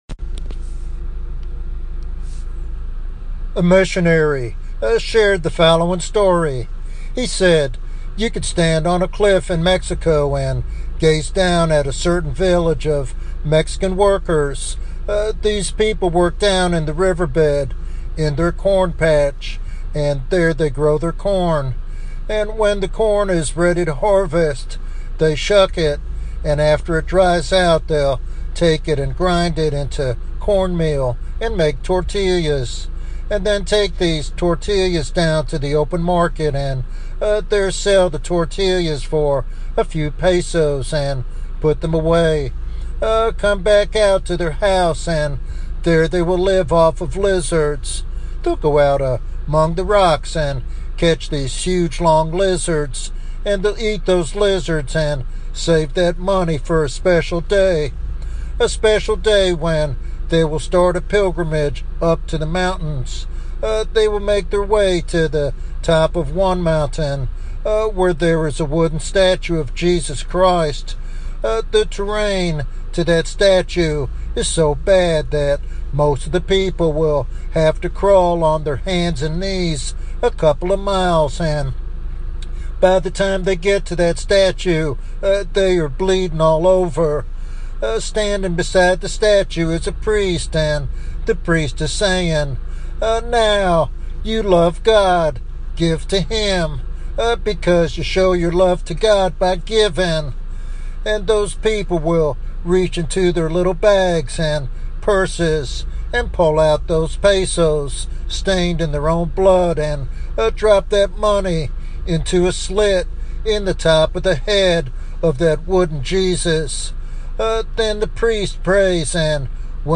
In this powerful evangelistic sermon